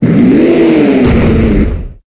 Cri de Boumata dans Pokémon Épée et Bouclier.